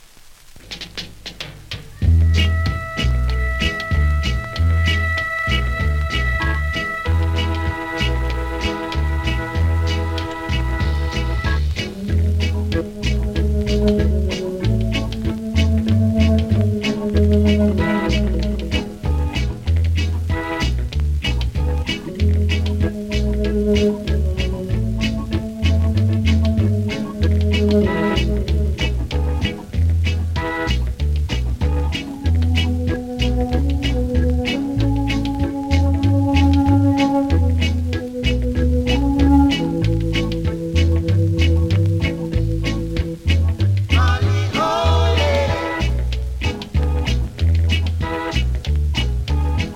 REGGAE 70'S
両面そこそこキズあり、多少ノイズもありますがプレイは問題無いレベル。